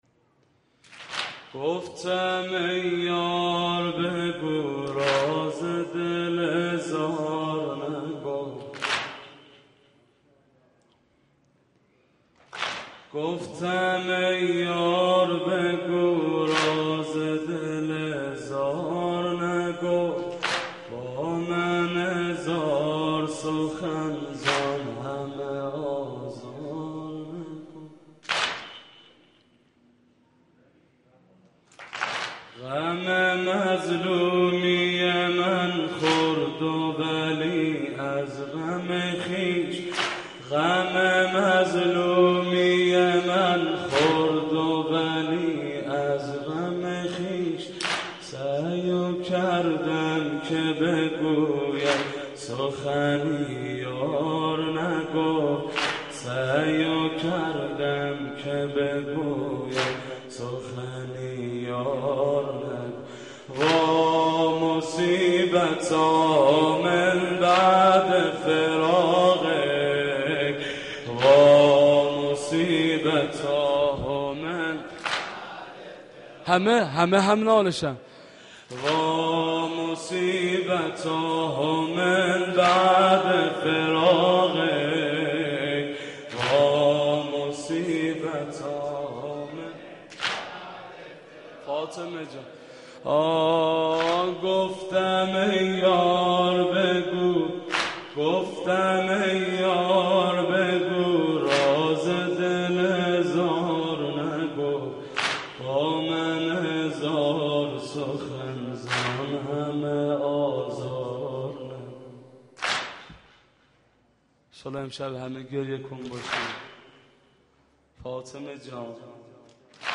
پادکست / مرثیه
دانلود / مداحی شهادت صدیقه کبری (علیها السلام)